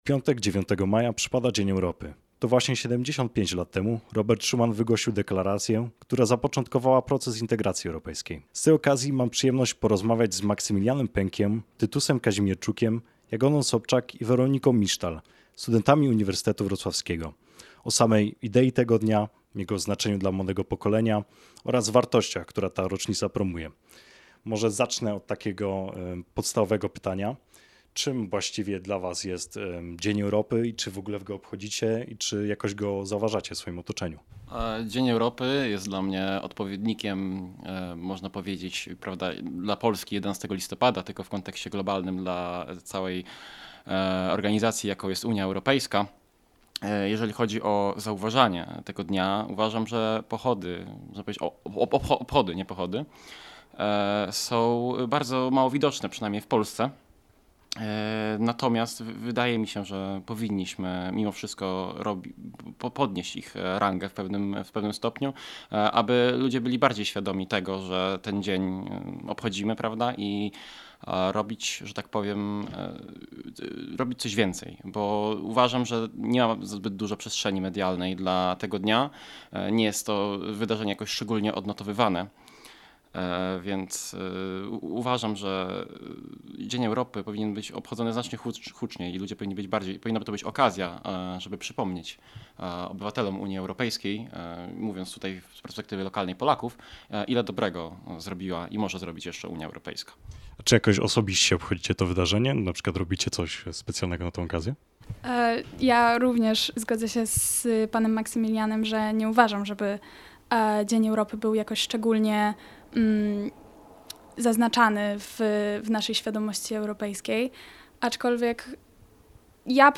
Dzień Europy oczami studentów. Rozmowa z okazji 75. rocznicy Deklaracji Schumana